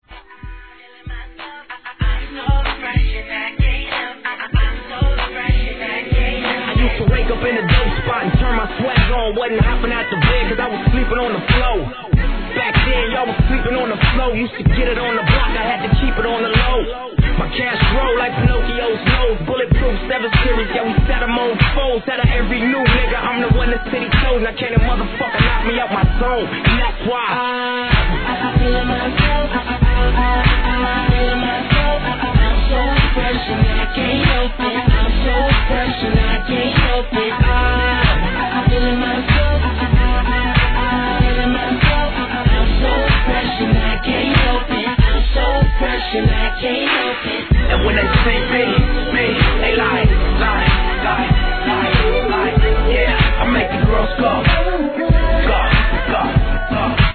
HIP HOP/R&B
[BPM94]    B1.